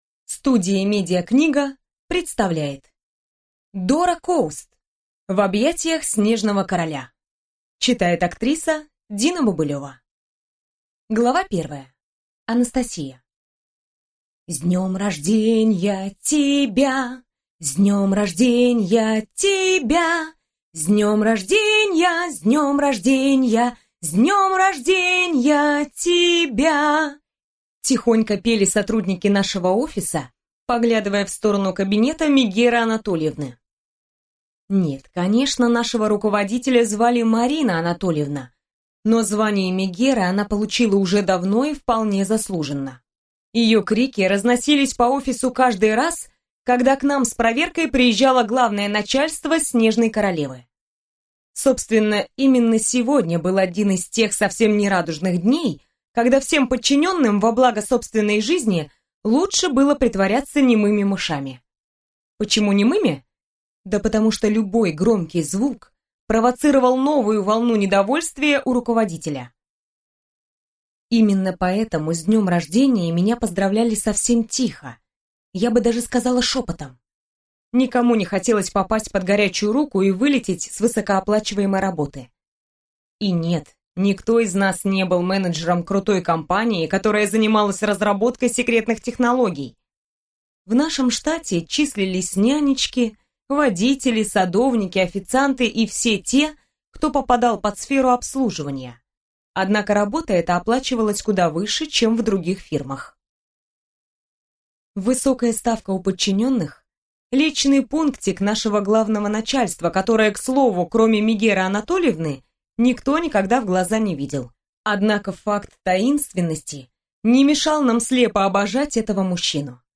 Аудиокнига В объятиях Снежного Короля | Библиотека аудиокниг
Прослушать и бесплатно скачать фрагмент аудиокниги